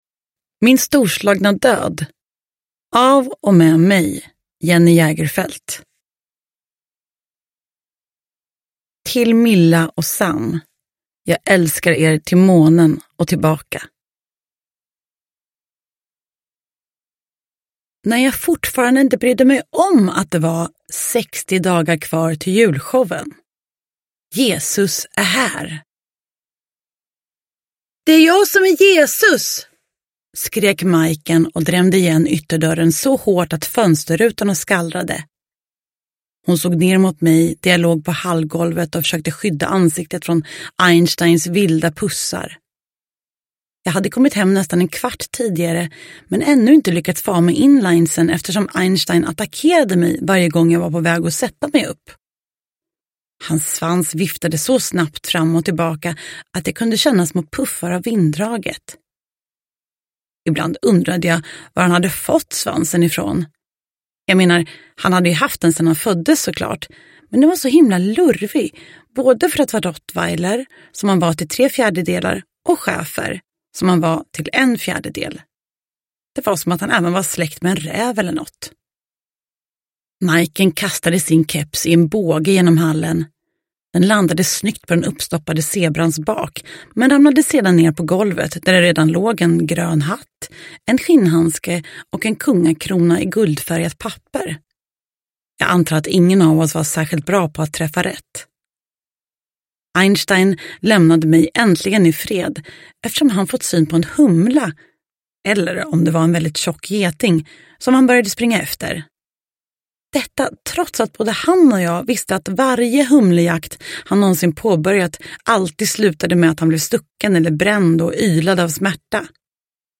Min storslagna död – Ljudbok – Laddas ner
Uppläsare: Jenny Jägerfeld